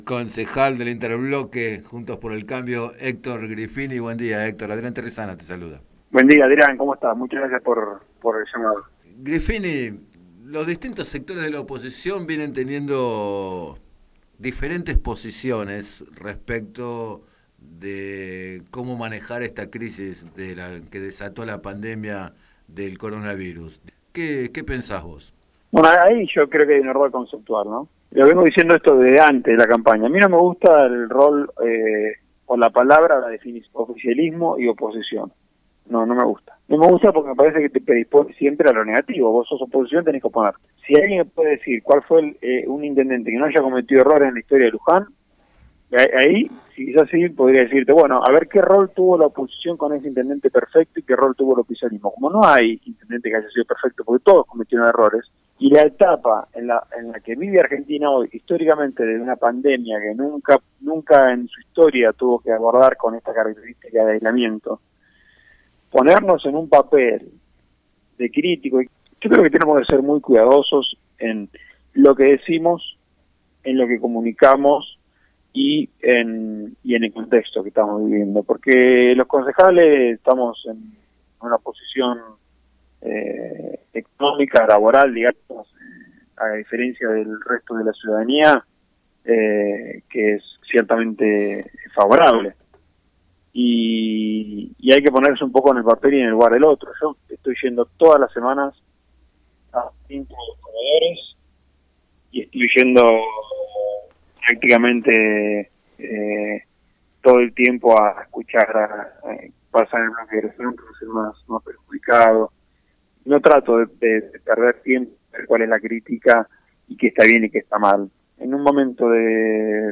En declaraciones a Radio Líder 97.7, Griffini llamó a evitar “la denuncia permanente que queda en la nada” y a “alinearse con el Ejecutivo” en la emergencia sanitaria.